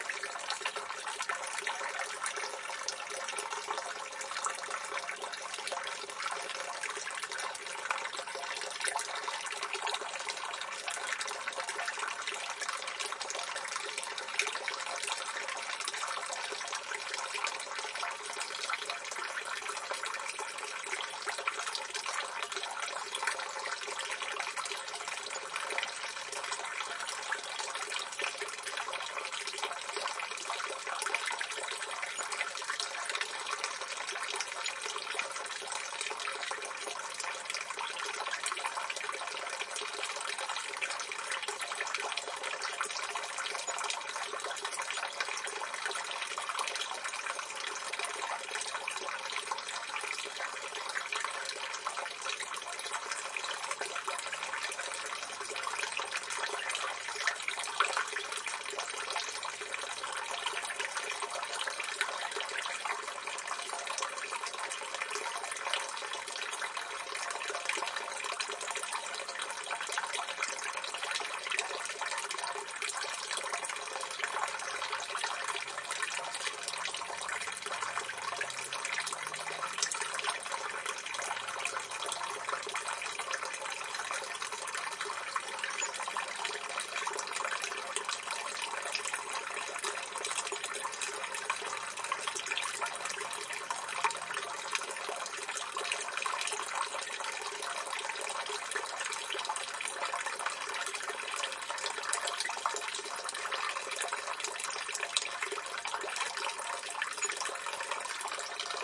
水 " 排水管
描述：水从小排水管循环流下来。录制了第五代iPod touch。用Audacity编辑。
标签： 滴流 漏极 纯粹 液体 排水管 飞溅
声道立体声